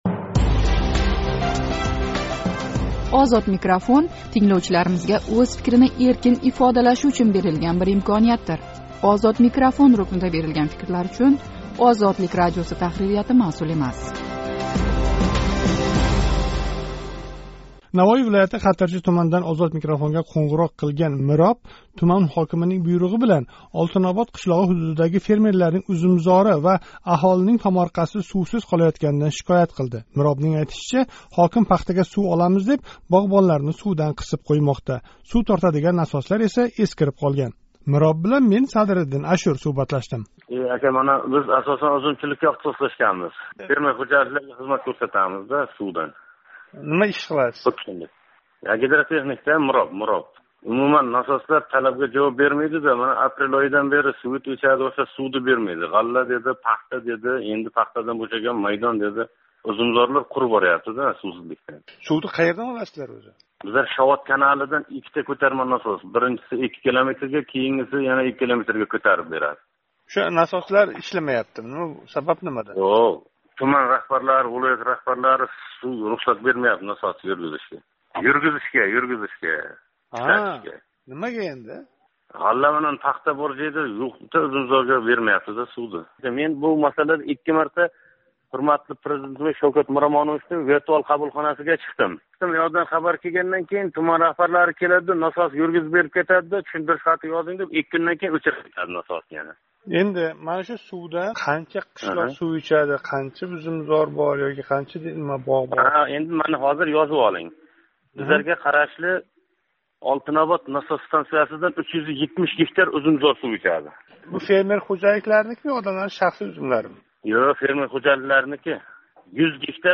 Хатирчилик мироб билан суҳбат